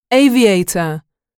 단어번호.0624 대단원 : 3 소단원 : a Chapter : 03a 직업과 사회(Work and Society)-Professions(직업) aviator [éivièitər] 명) 비행사 mp3 파일 다운로드 (플레이어바 오른쪽 아이콘( ) 클릭하세요.)
aviator.mp3